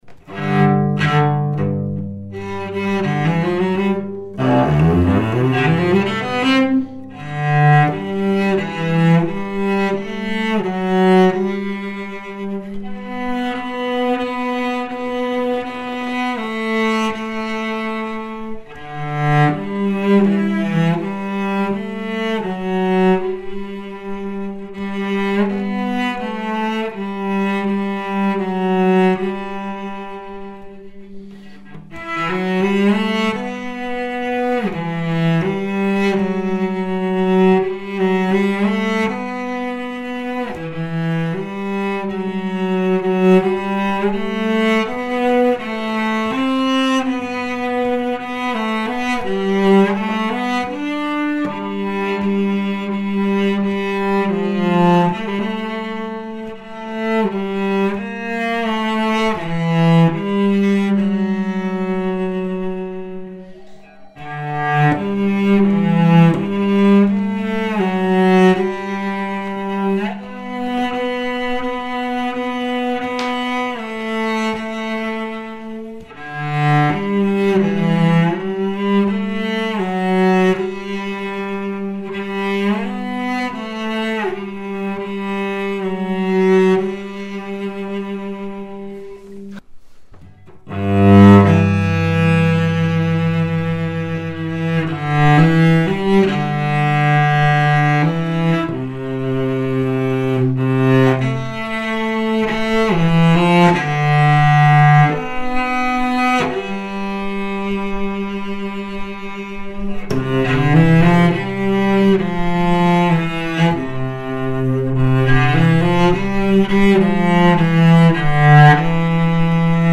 演奏者紹介)さんをお迎えし、試奏と以下のそれぞれのチェロの講評をして頂きました。
珍しいワンピースバックのチェロです。鳴ります！！